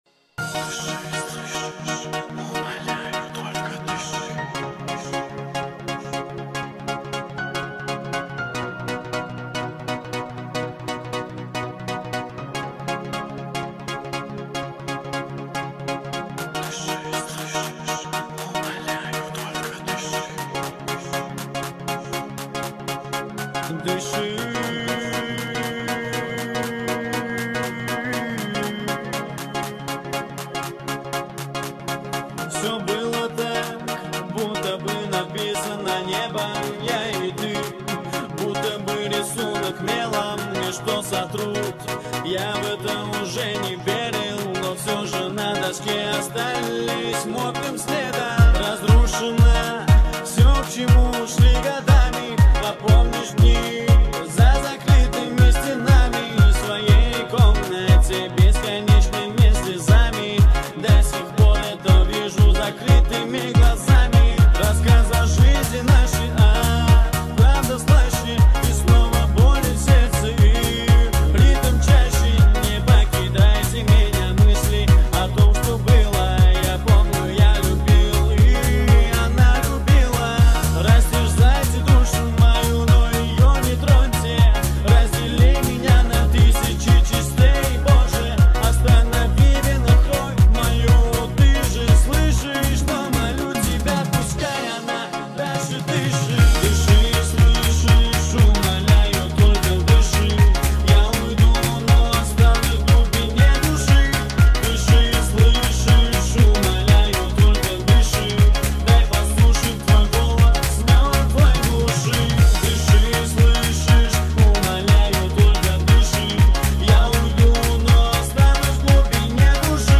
лезгинка тик ток ремикс